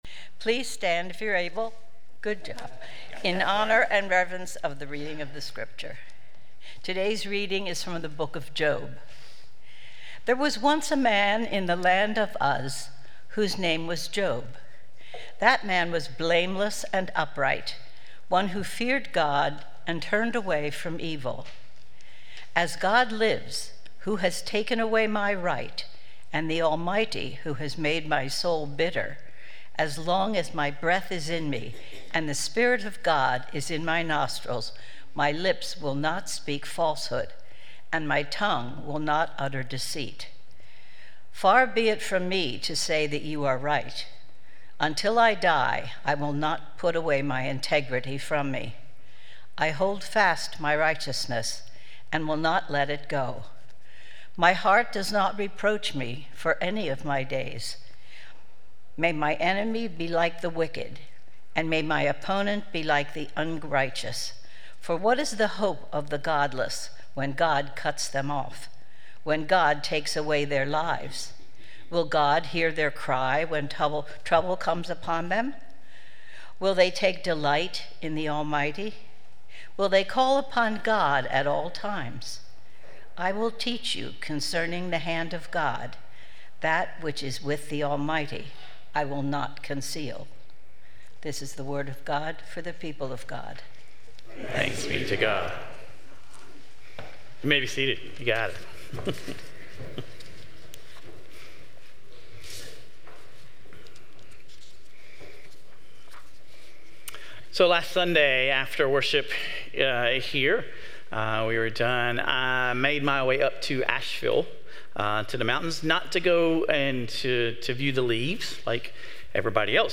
In this sermon, we tackle this difficult question and how we might need to “unlearn life.” Sermon Reflections: In the sermon, we're reminded that life isn't always fair, and even good people face difficulties.